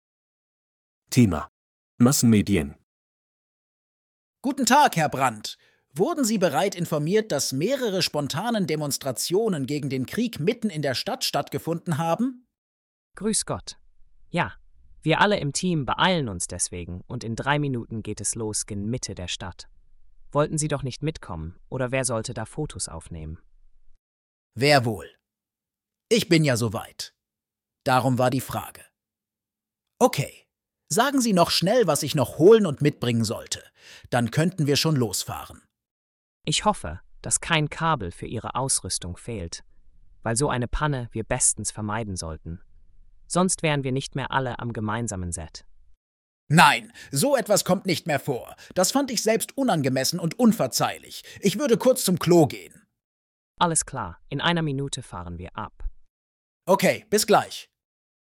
Audio text for the dialogs in task 4: